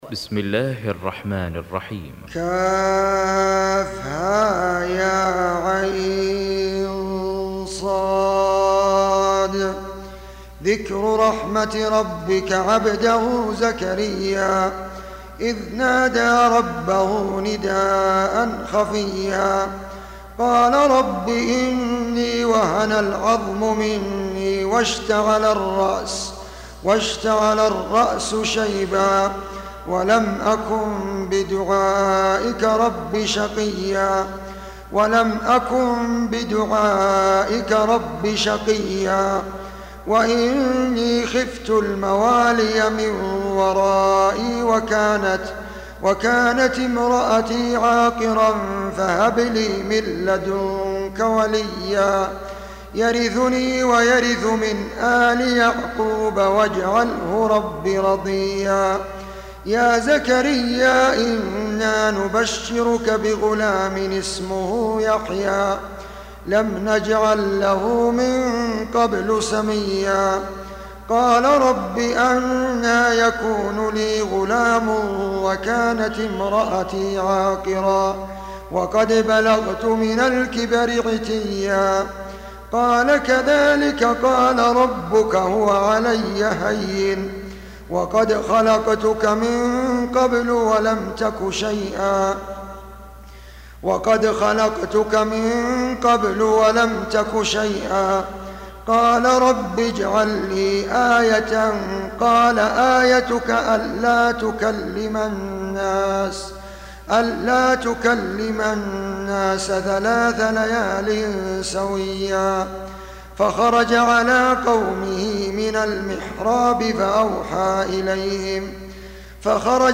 Surah Repeating تكرار السورة Download Surah حمّل السورة Reciting Murattalah Audio for 19. Surah Maryam سورة مريم N.B *Surah Includes Al-Basmalah Reciters Sequents تتابع التلاوات Reciters Repeats تكرار التلاوات